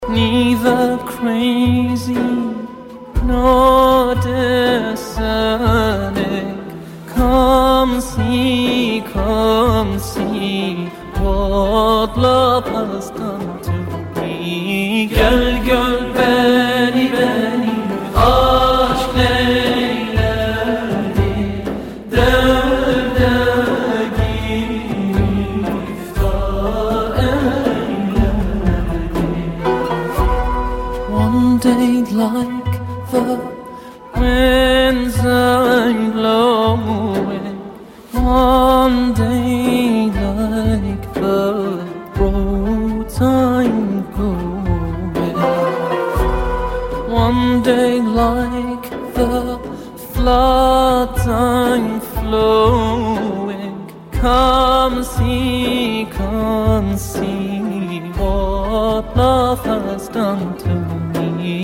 زنگ موبایل عاشقانه و محزون(با کلام)
با گروه همخوانی